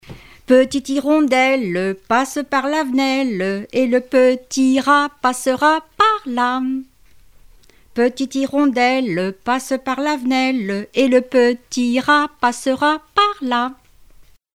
enfantine : comptine
Répertoire de chansons populaires et traditionnelles
Pièce musicale inédite